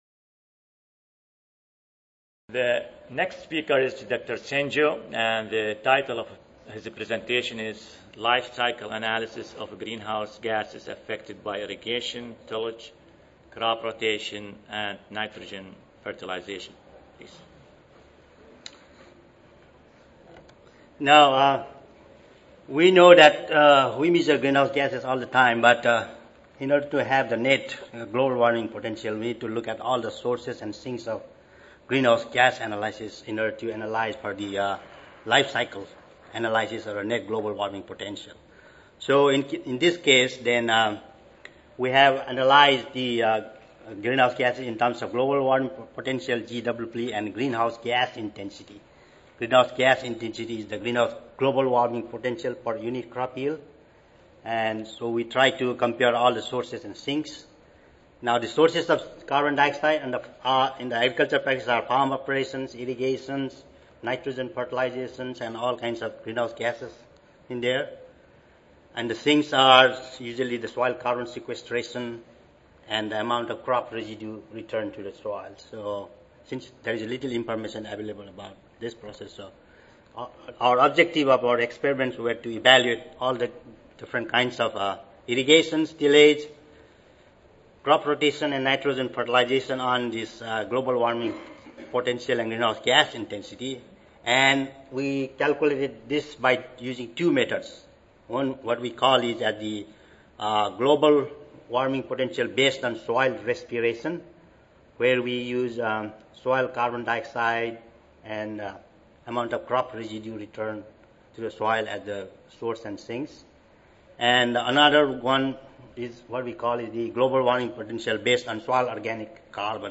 USDA-ARS Recorded Presentation Audio File